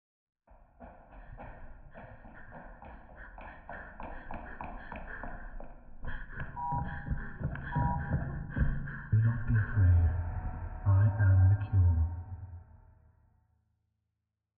Commotion21.ogg